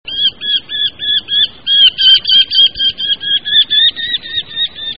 En cliquant ici vous entendrez le chant du Faucon crécerelle.